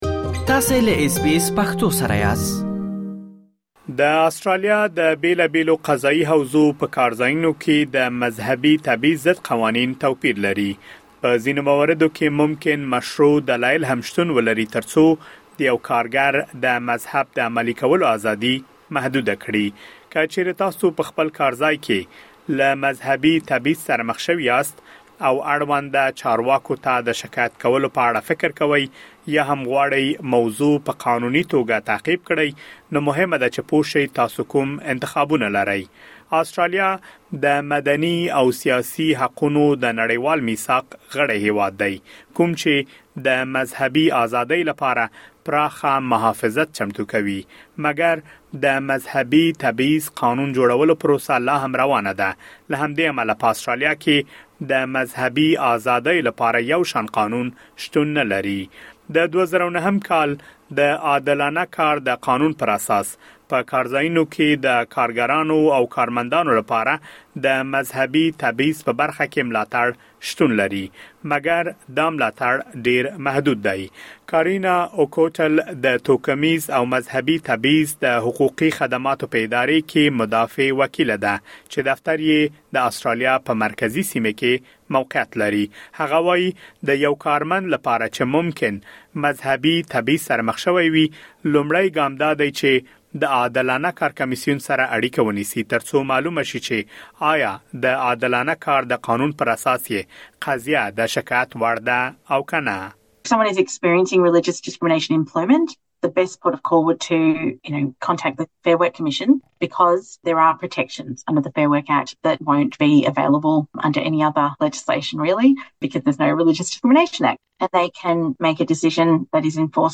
د آسټراليا د بېلابېلو قضايي حوزو په کارځايونو کې د مذهبي تبعیض ضد قوانين توپير لري. د آسټرالیا پېژندنې په دغه رپوټ کې د مذهبي تبعیض په اړه مهم معلومات اورېدلی شئ.